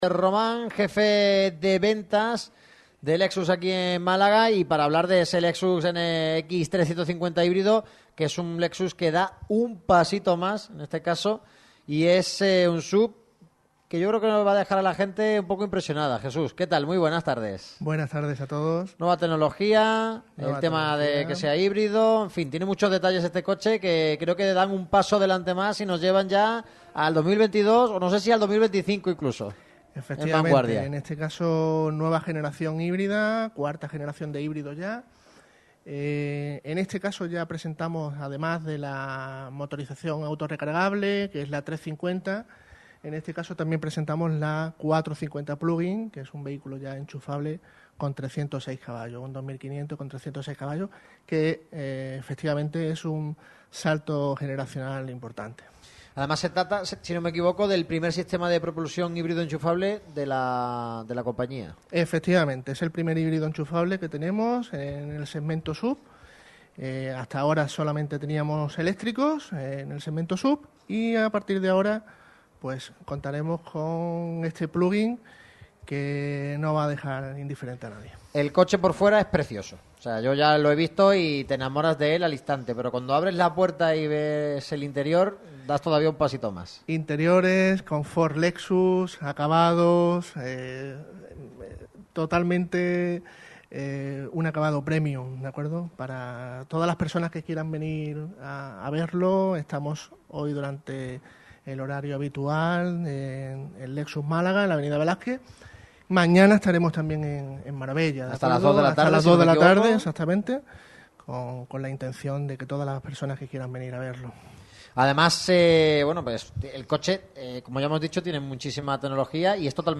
Radio Marca Málaga cierra la semana en el concesionario oficial de Lexus, en plena Avenida Velázquez.